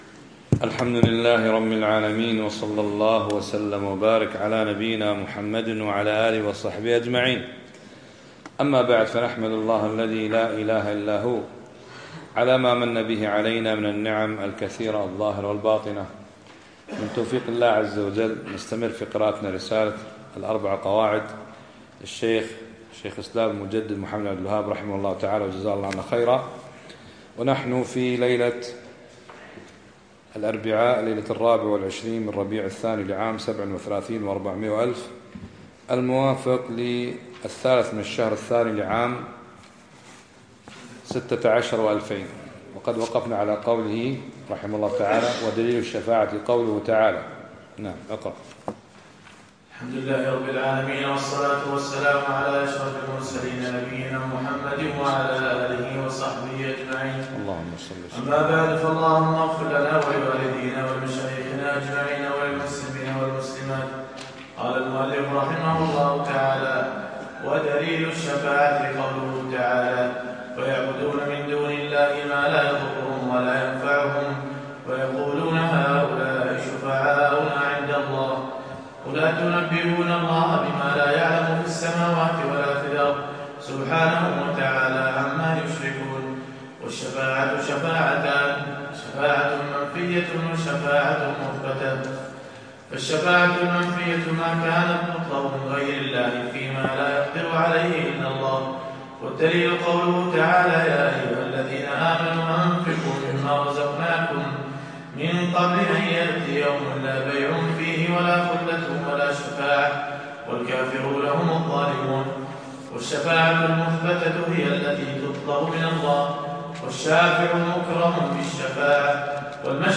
يوم الثلاثاء 23 ربيع الأخر 1437هـ الموافق 2 2 2016م في مسجد سعد السلطان الفنطاس
شرح القواعد الأربع الدرس الثالث